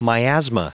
Prononciation du mot miasma en anglais (fichier audio)
Prononciation du mot : miasma